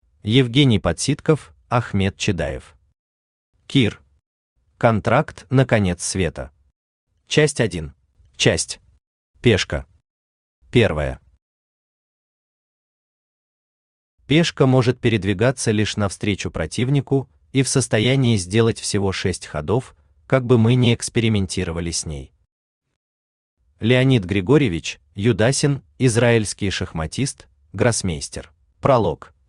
Аудиокнига Кир. Контракт на конец света. Часть 1 | Библиотека аудиокниг
Часть 1 Автор Евгений Подситков Читает аудиокнигу Авточтец ЛитРес.